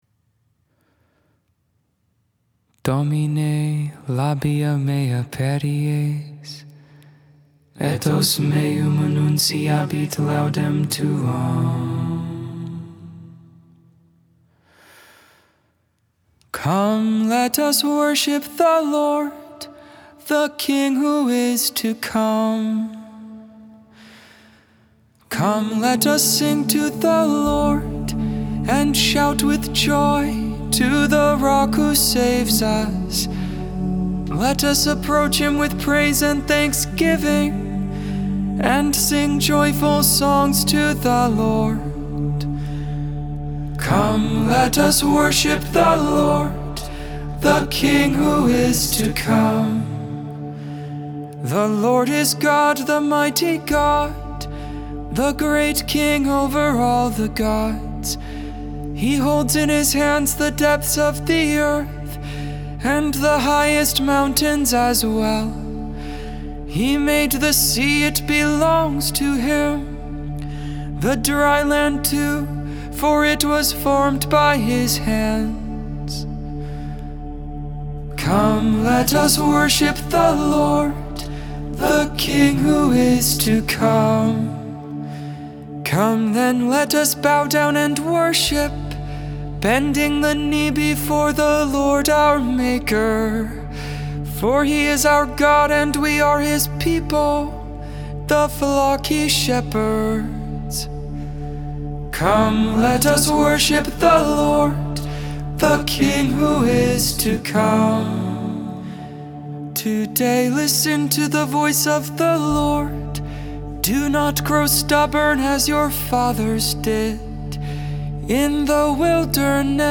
Invitatory, Psalm 95 (Gregorian tone 8)